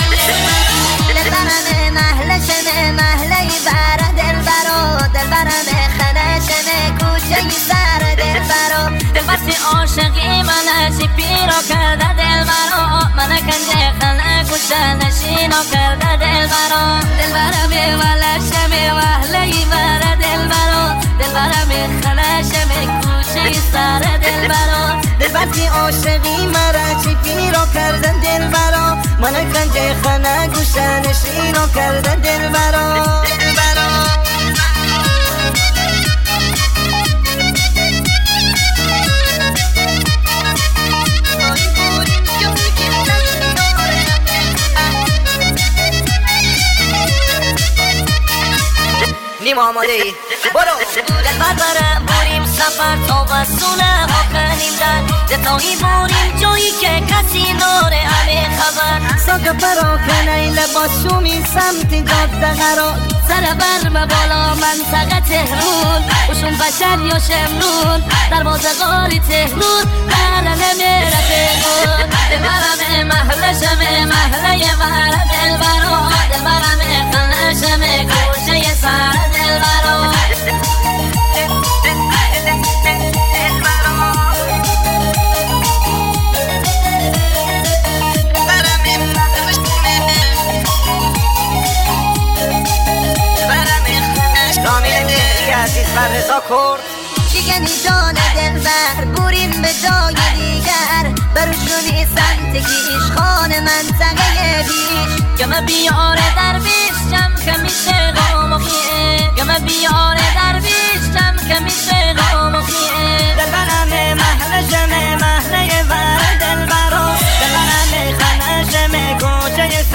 آهنگ مازندرانی شاد باحال